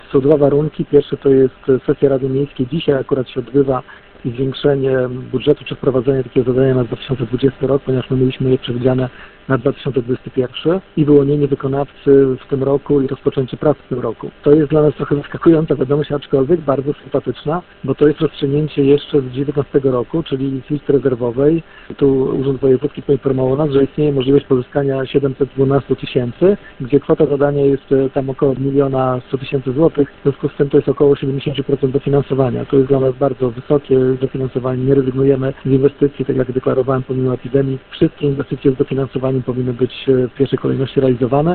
– Takie są warunki, by otrzymać dotację na remont ul. Sadowej z Funduszu Dróg Samorządowych- tłumaczy włodarz.
Pierwszym krokiem, by sięgnąć po finansowe wsparcie i zrealizować inwestycję potrzebna jest dziś decyzja radnych – podkreśla burmistrz.